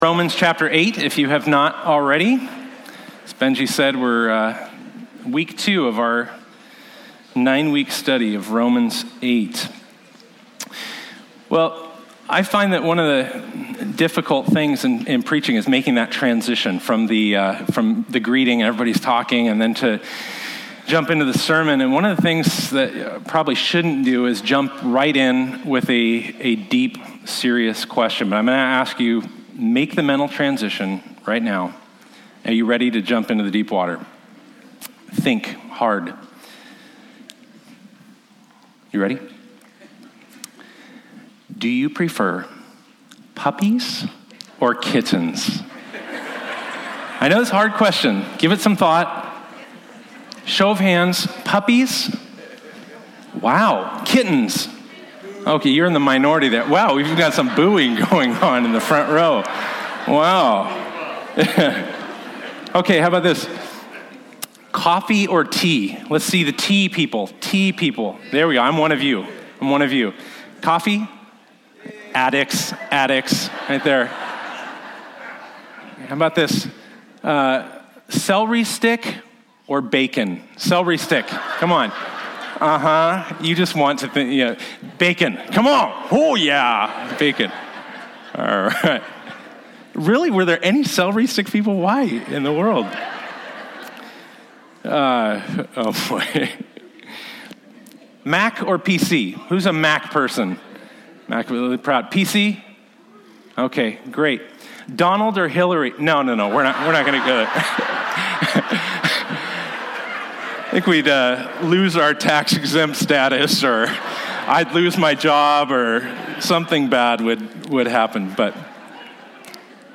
Bible Text: Romans 8:5-11 | Preacher